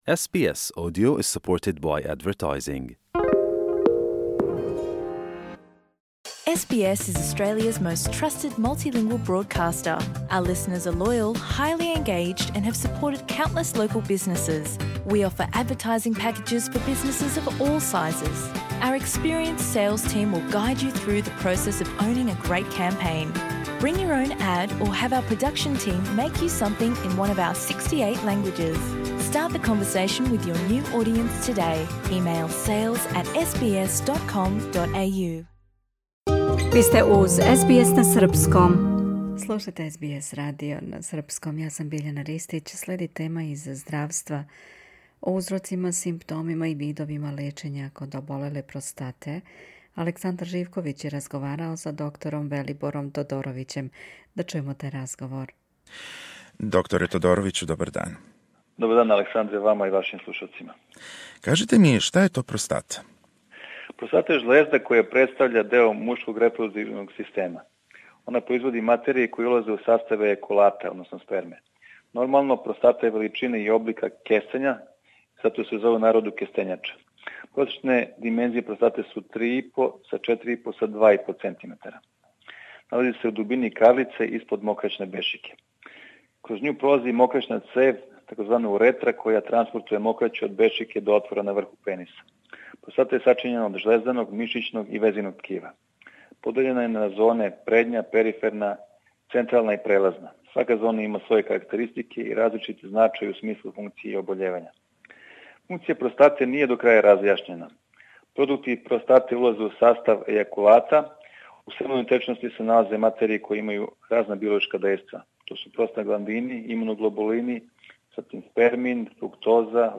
Интервју из СБС архива, оригинално објављен 1. јануара 2017 Share